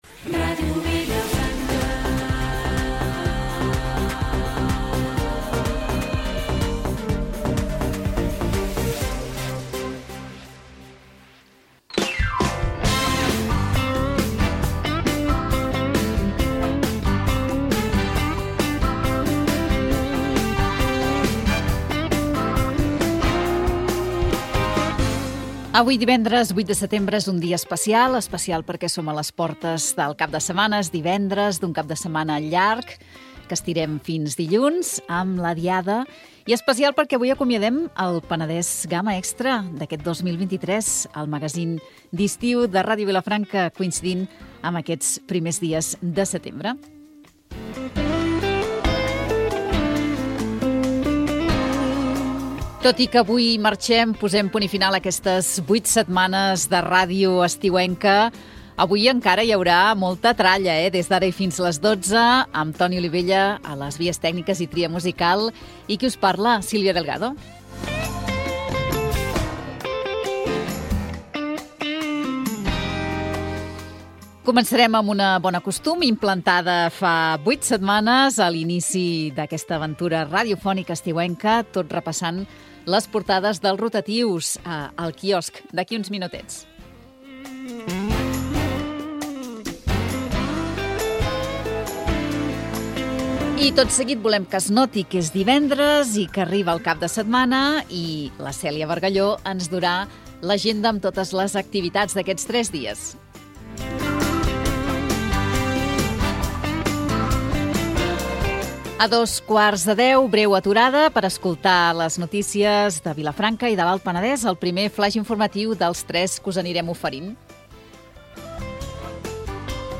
Indicatiu de l'emissora, presentació del programa, sumari, repàs a les portades dels diaris digitals
FM